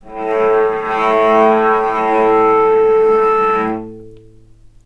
healing-soundscapes/Sound Banks/HSS_OP_Pack/Strings/cello/sul-ponticello/vc_sp-A2-mf.AIF at bf8b0d83acd083cad68aa8590bc4568aa0baec05
vc_sp-A2-mf.AIF